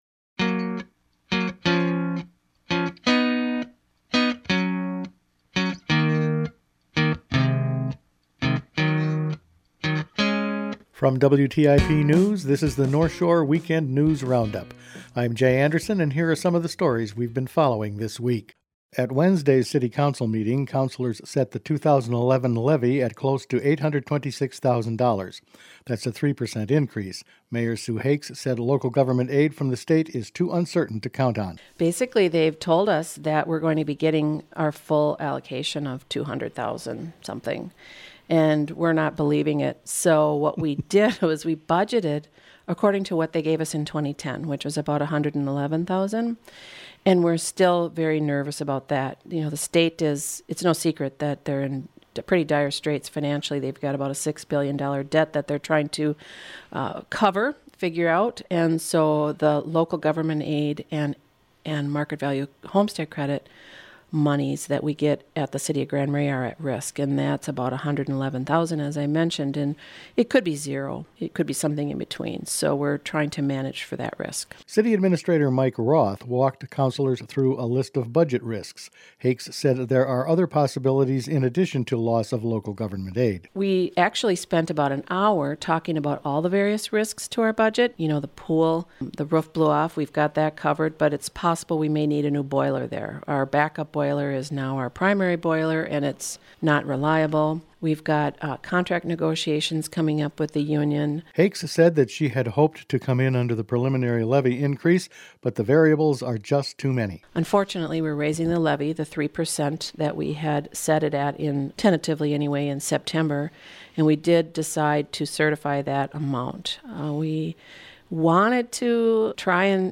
Weekend News Roundup for Dec. 11